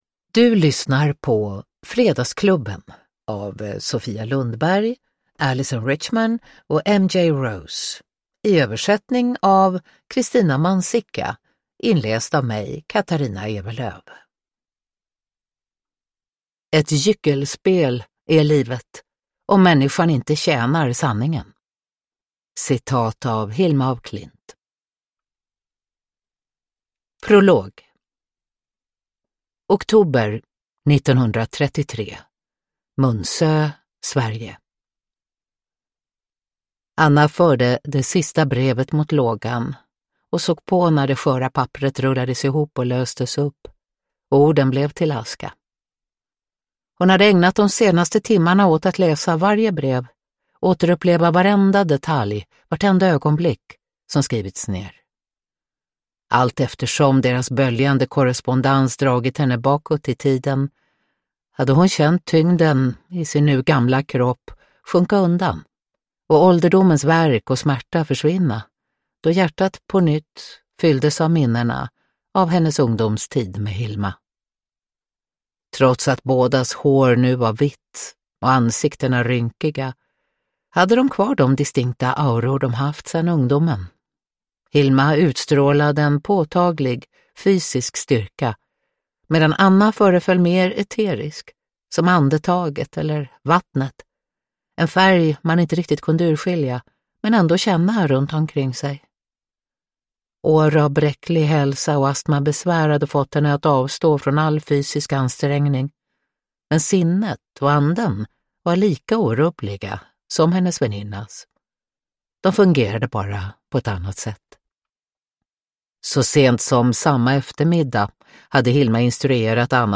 Fredagsklubben – Ljudbok – Laddas ner
Uppläsare: Katarina Ewerlöf